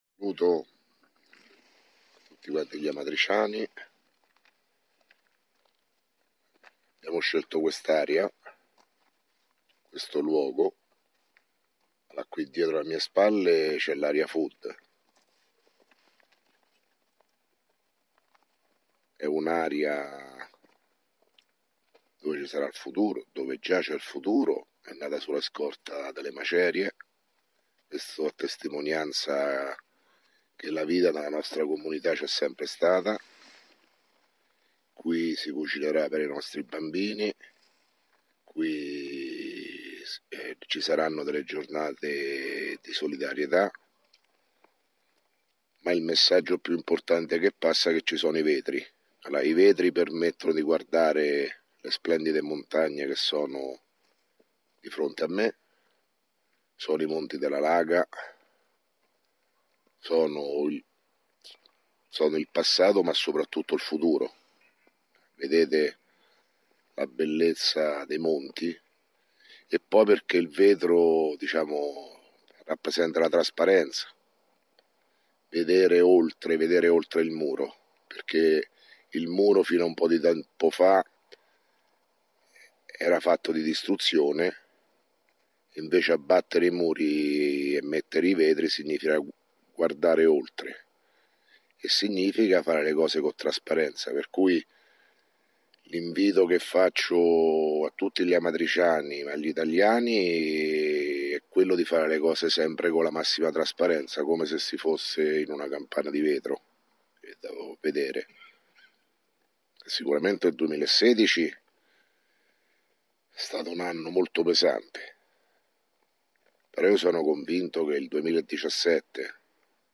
Di seguito il messaggio audio del Sindaco Sergio Pirozzi, del 31 dicembre 2016.